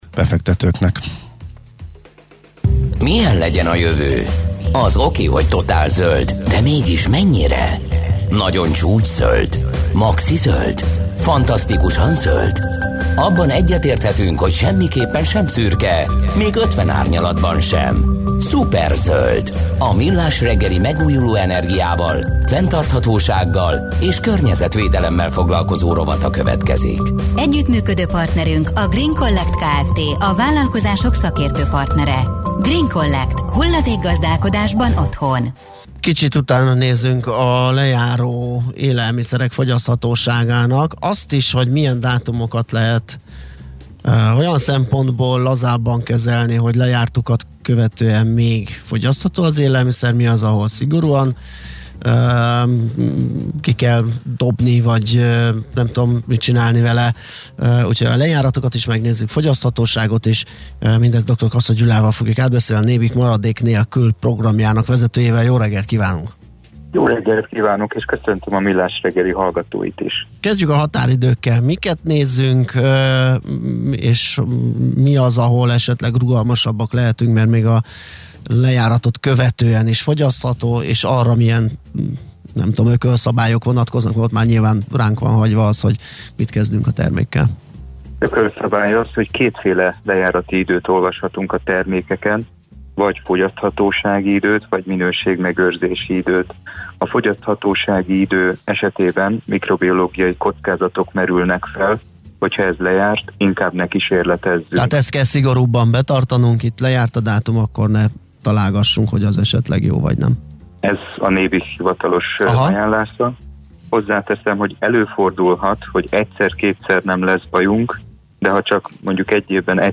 Műfaj: Blues.